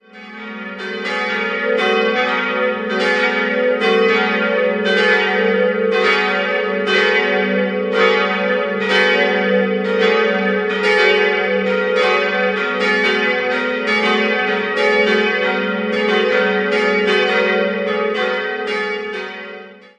3-stimmiges Paternoster-Geläute: ges'-as'-b' Die beiden größeren Glocken wurden 1950 von Karl Czudnochowsky in Erding gegossen, die kleine stammt aus der Gießerei Bachmaier in Ingolstadt und entstand 1896.